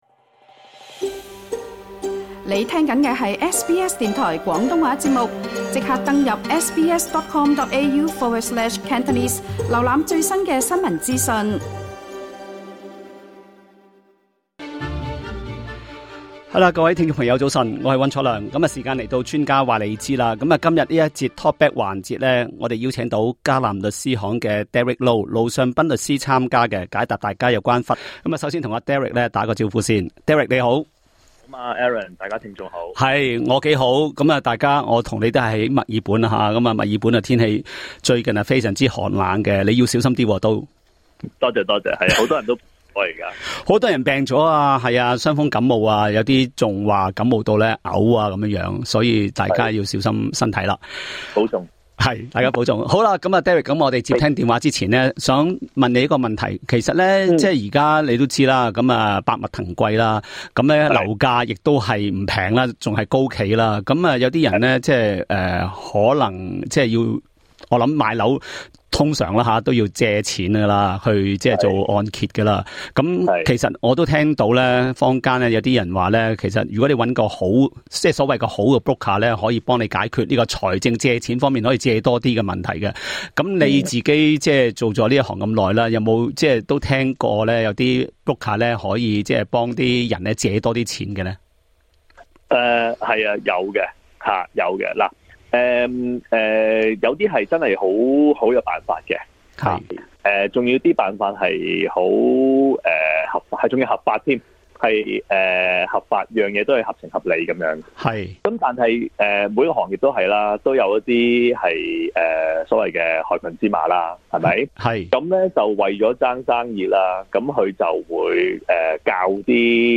另外，他還解答聽眾的提問，包括有聽眾表示自己剛下機不久，駕車時非常疲憊，並打了一秒的瞌睡，結果自己的車撞上了停泊在街旁的兩輛汽車。